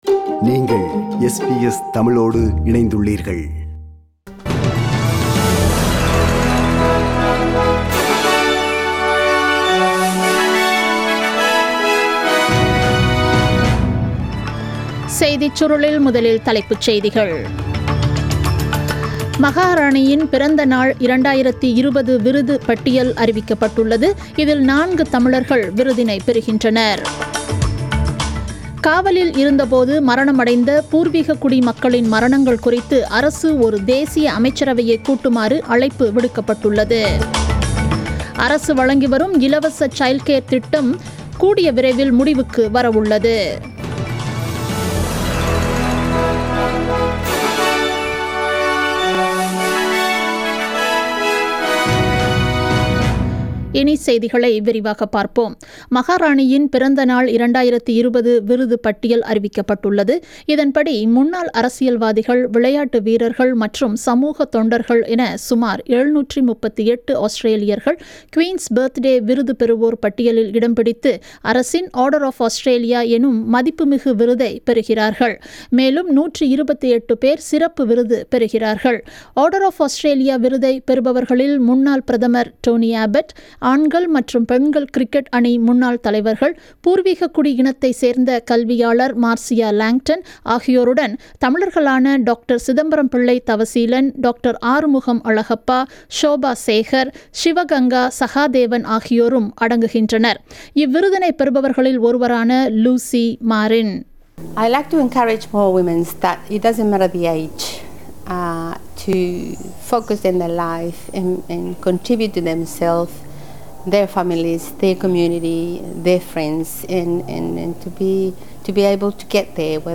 The news bulletin aired on 8th June 2020 at 8pm